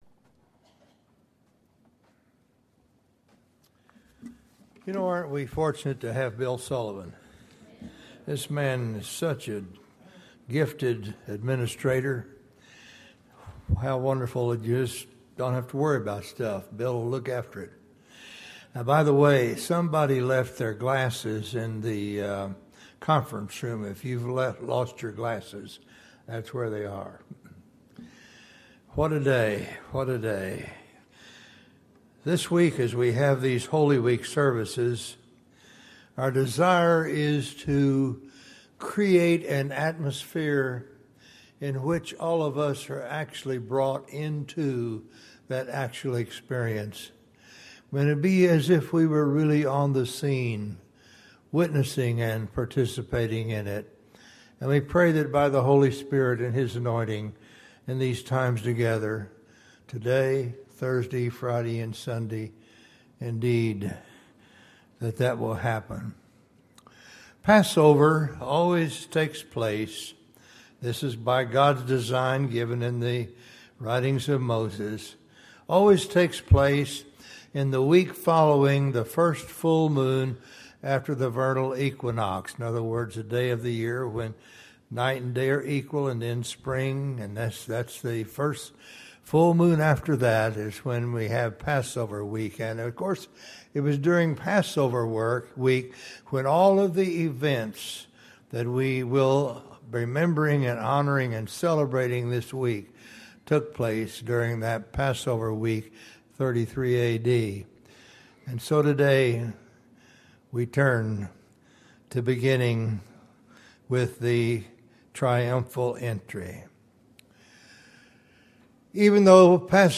Tulsa Christian Fellowship Sermon Audio